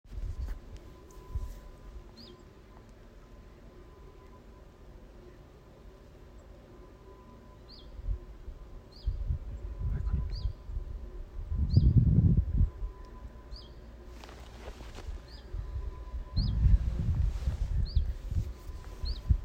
Putni -> Ķauķi -> 1
Vītītis, Phylloscopus trochilus
Administratīvā teritorijaRīga